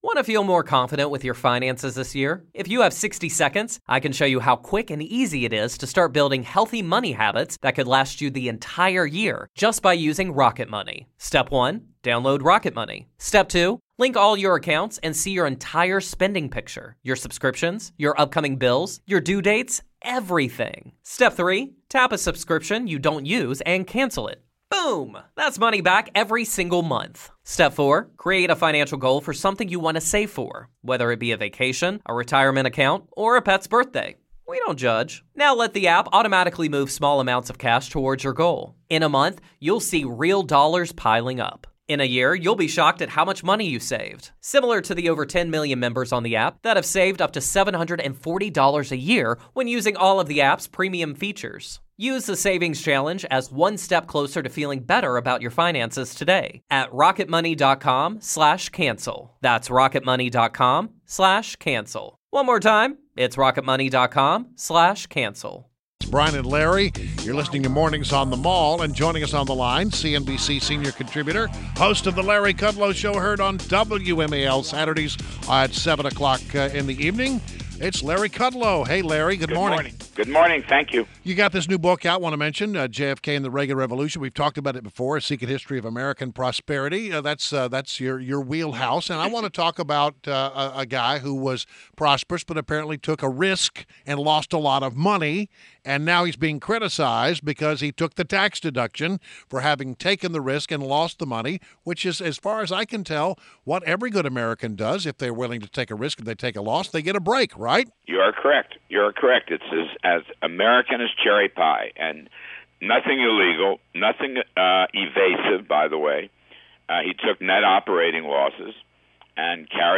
INTERVIEW — LARRY KUDLOW – CNBC Senior Contributor and host of The Larry Kudlow Show on WMAL Saturdays at 7 pm